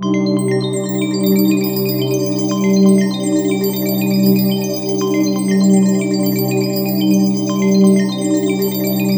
SEQ PAD04.-R.wav